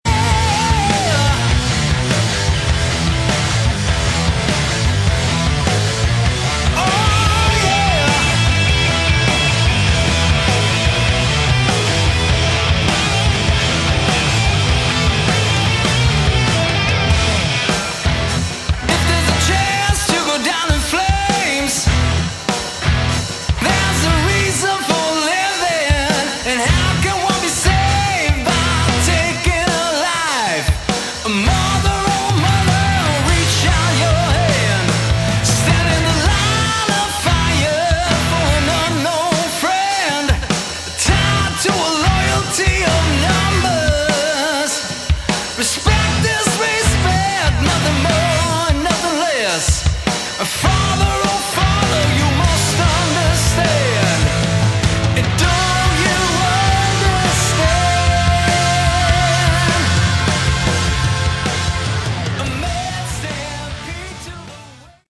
Category: Melodic Hard Rock
lead & backing vocals
lead & rhythm guitar, backing vocals
bass, backing vocals
drums, percussion, backing vocals
organ, keyboards, backing vocals